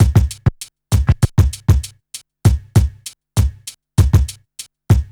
1TI98BEAT3-R.wav